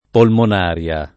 polmonaria [ polmon # r L a ]